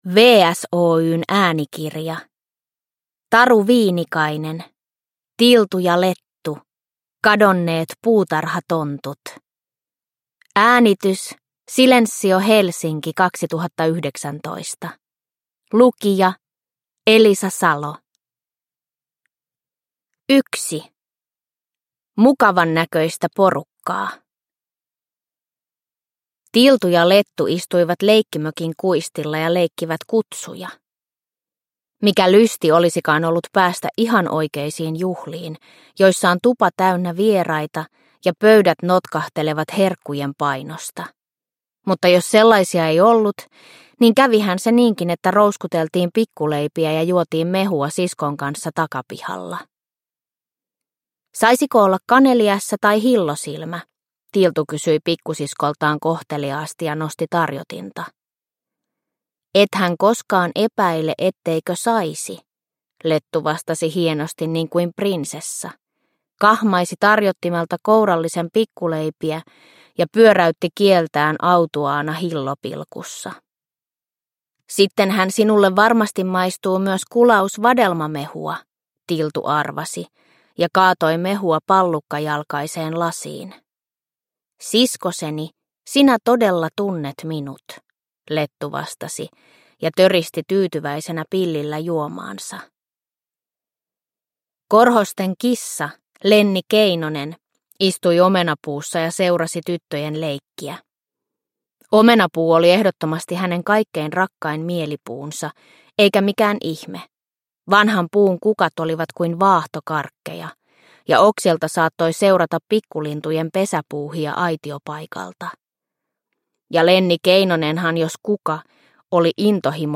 Tiltu ja Lettu - Kadonneet puutarhatontut – Ljudbok – Laddas ner